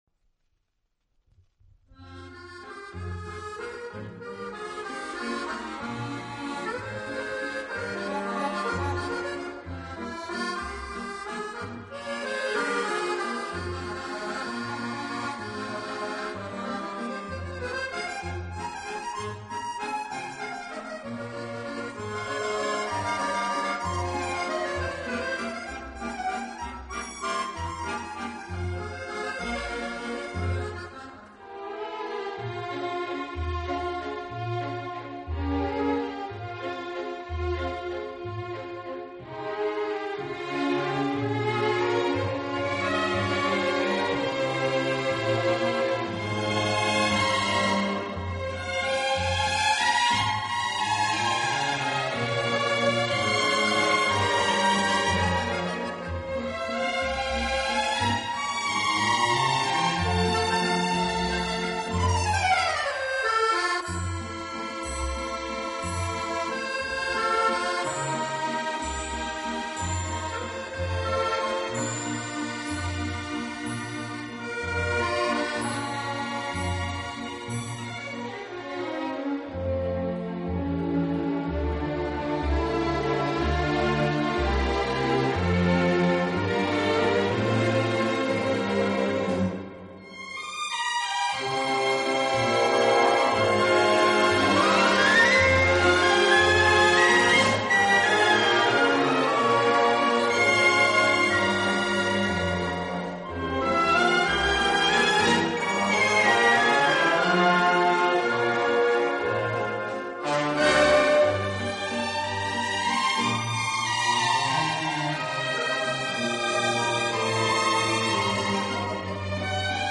Genre: Instrumental
舒展，旋律优美、动听，音响华丽丰满。